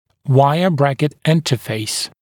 [‘waɪə-‘brækɪt ‘ɪntəfeɪs][‘уайэ-‘брэкит ‘интэфэйс]область взаимодействия дуги и брекета